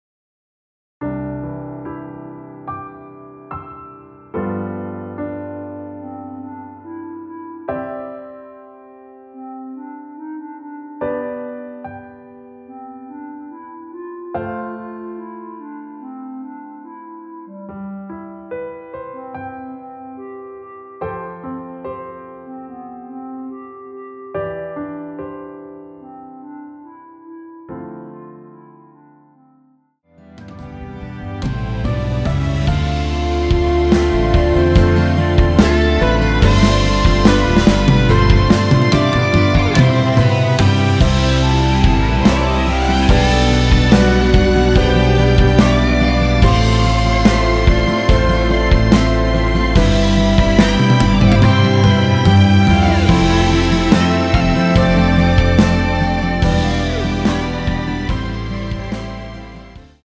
노래가 바로 시작 하는 곡이라 전주 만들어 놓았습니다.
6초쯤에 노래 시작 됩니다.(미리듣기 참조)
노래방에서 노래를 부르실때 노래 부분에 가이드 멜로디가 따라 나와서
앞부분30초, 뒷부분30초씩 편집해서 올려 드리고 있습니다.
중간에 음이 끈어지고 다시 나오는 이유는